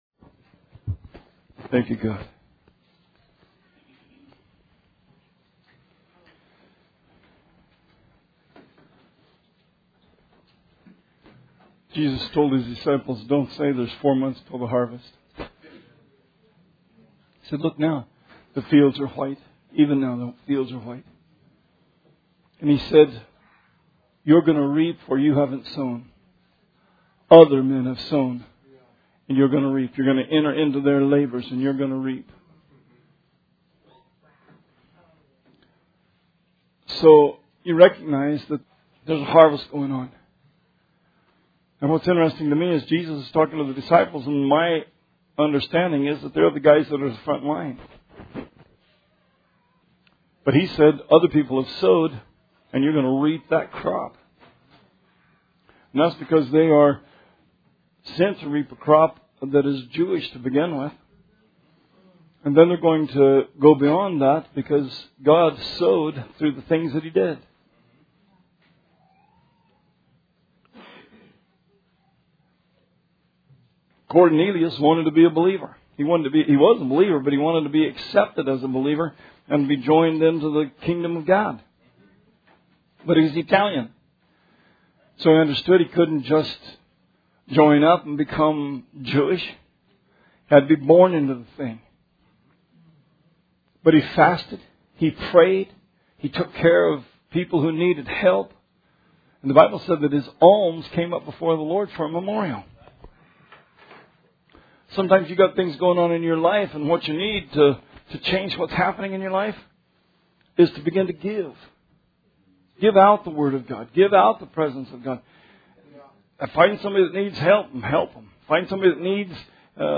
Sermon 5/14/17